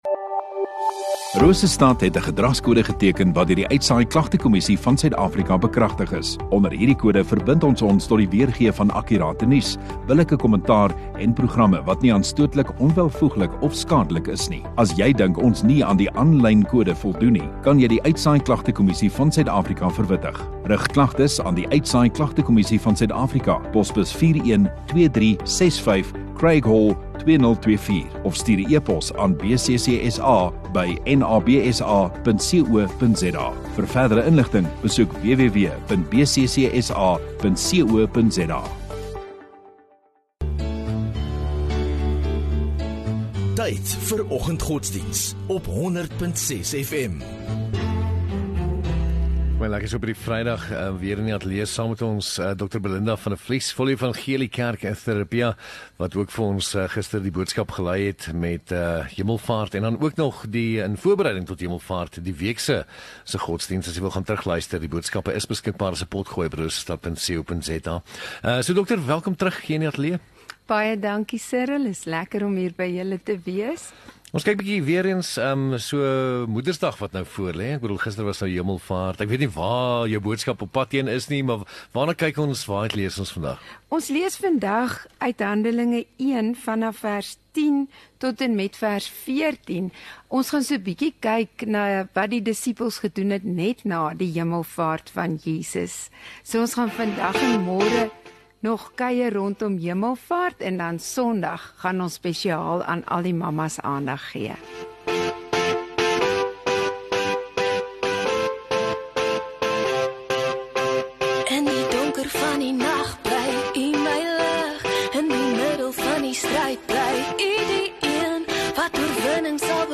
10 May Vrydag Oggenddiens